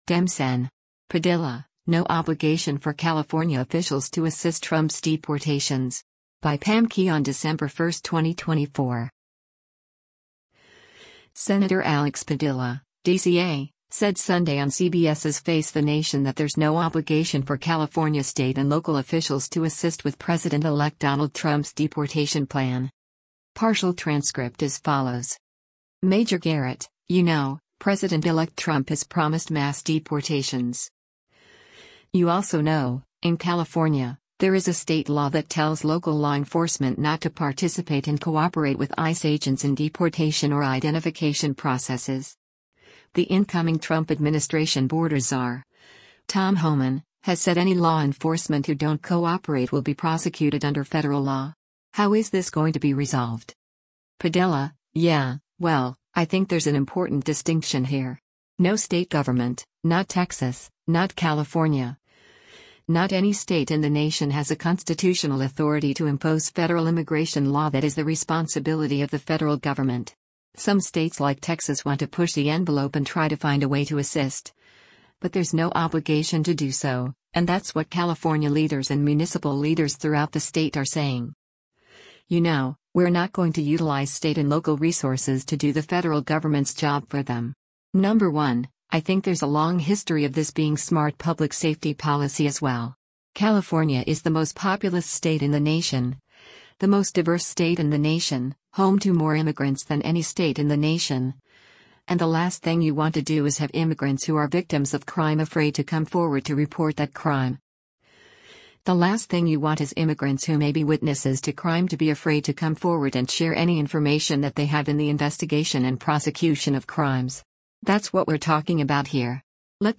Senator Alex Padilla (D-CA) said Sunday on CBS’s “Face The Nation” that “there’s no obligation” for California state and local officials to assist with President-elect Donald Trump’s deportation plan.